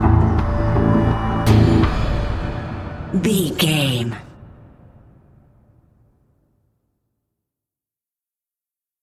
Aeolian/Minor
synthesiser
drum machine
ominous
dark
suspense
haunting
creepy